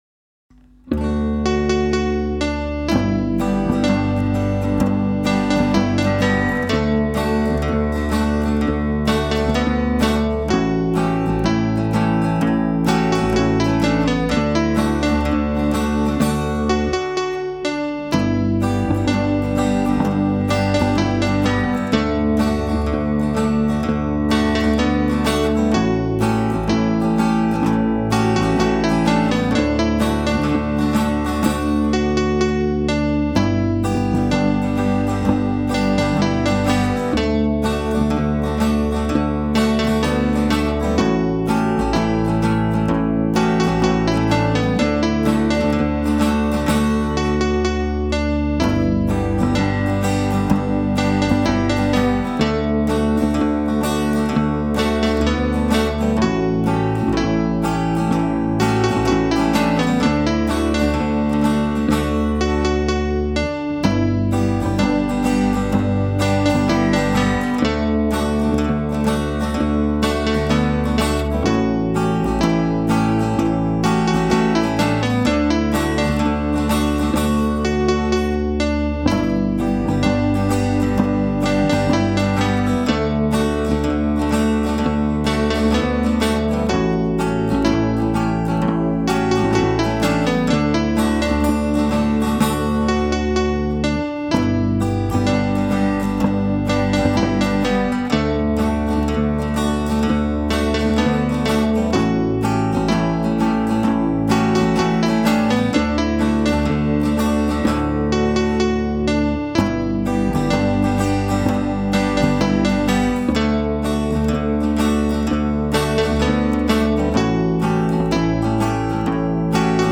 Übe den Refrain von Midnight Special, indem Du mit der Gesangsmelodie mitsingst.